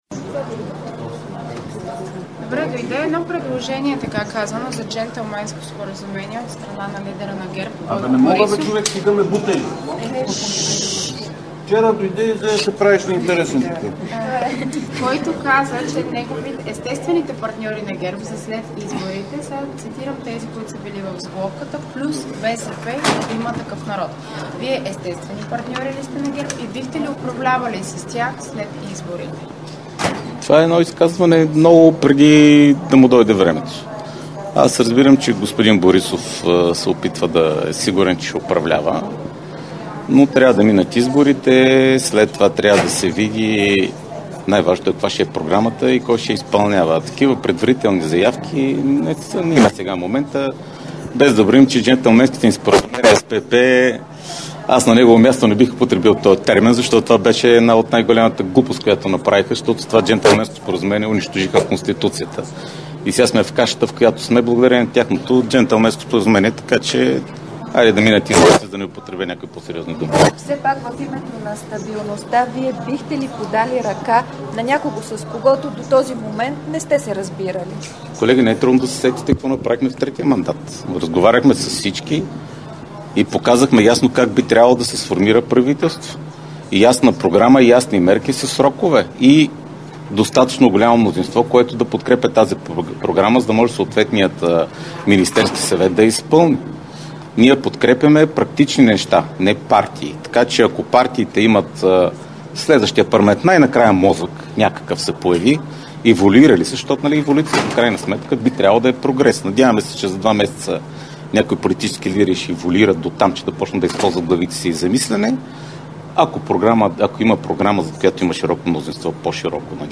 11.50 - Брифинг на Корнелия Нинова. - директно от мястото на събитието (Народното събрание)
Директно от мястото на събитието